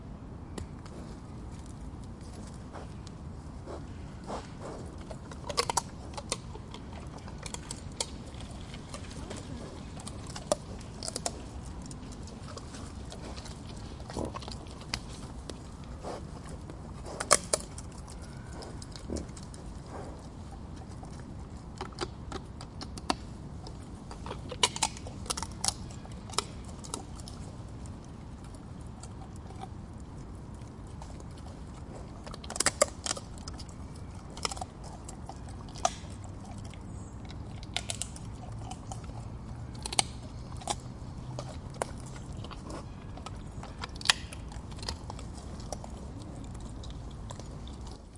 野猪动画片
描述：用我的声音为个人项目生成，用Sony ICDUX512立体声录制。
标签： 卡通 呼噜声 野猪 野生 叫声
声道立体声